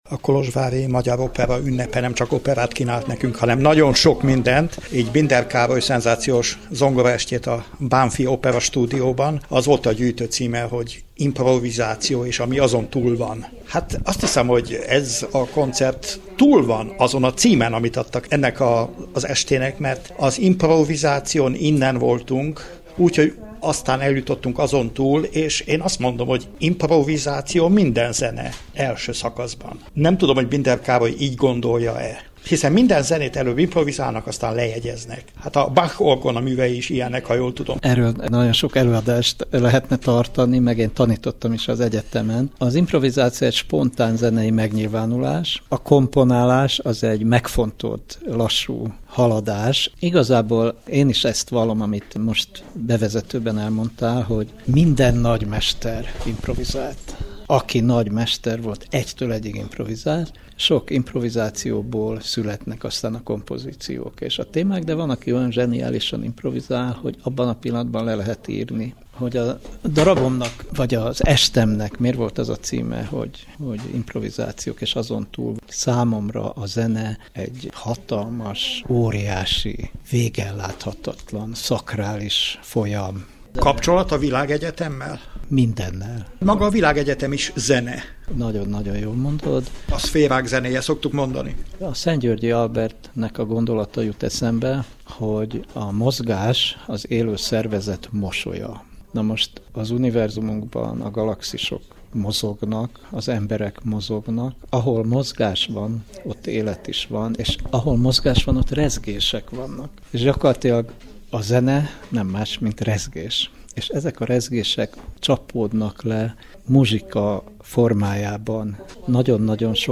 Binder Károly zongoraművész, zeneszerző, a magyar jazz egyik legnagyobb alakja lépett fel a Kolozsvári Magyar Opera által megrendezett 5. Opera Napokon. A nagysikerű koncertet követően a Kolozsvári Rádiónak is nyilatkozott az előadó.